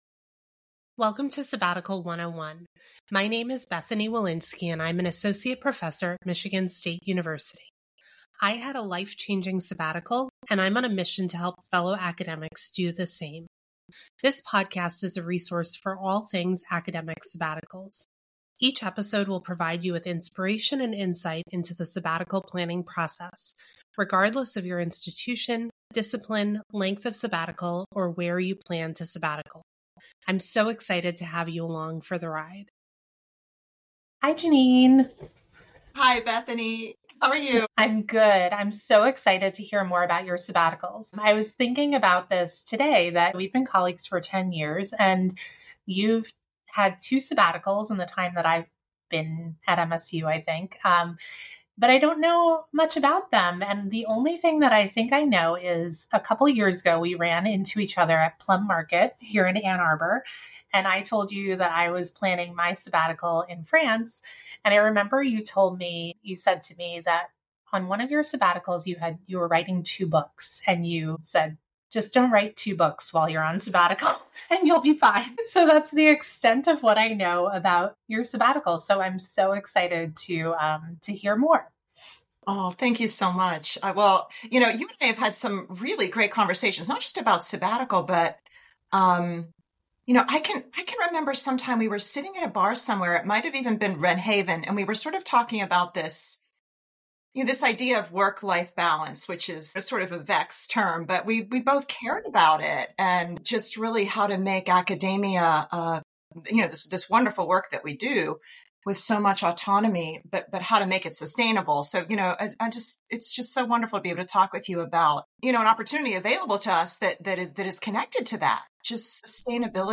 She has such a calming presence, and I think that sense of calm comes through in this episode.